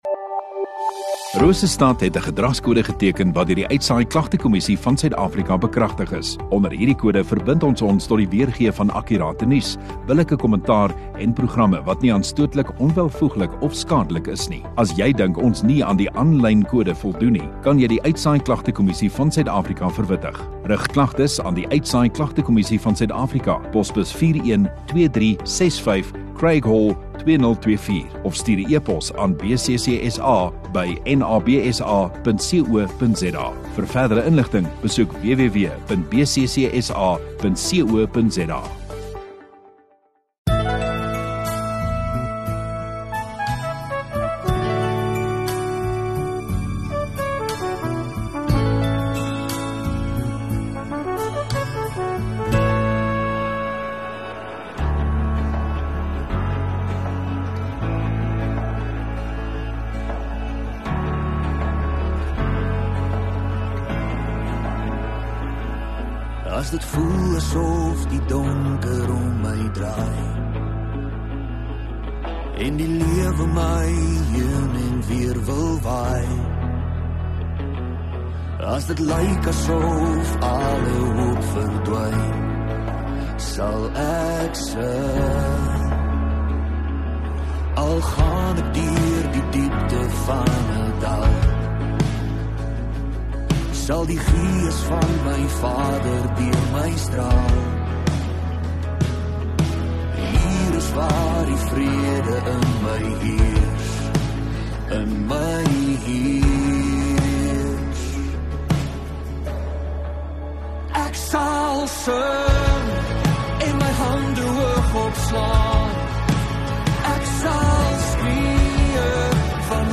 Rosestad Godsdiens 20 Oct Sondagoggend Erediens